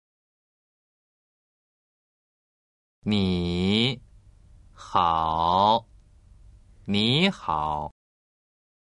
発音する時には níhǎo と発音します。
第３声の声調変化